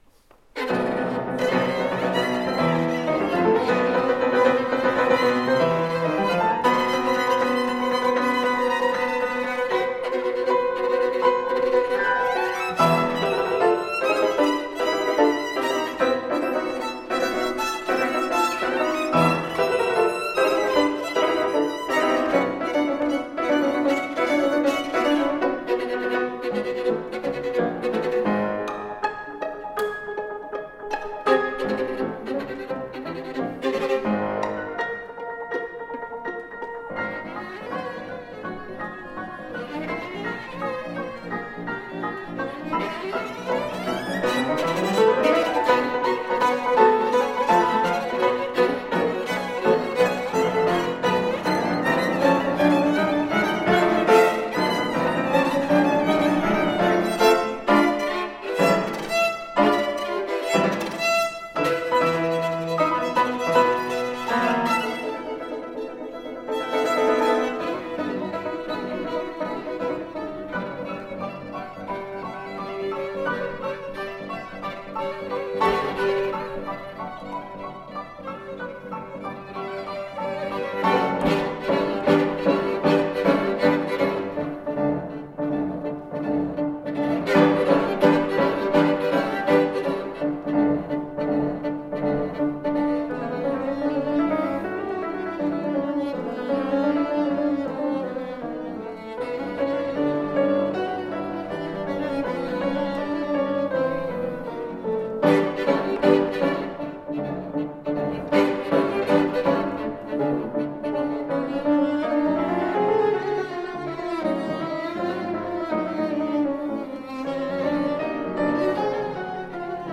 Artist Faculty Concert recordings - July 9, 2014 | Green Mountain Chamber Music Festival
violin
piano